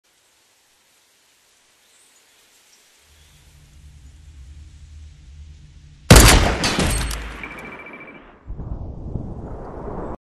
fortnite-moving-headshot-snipe.mp3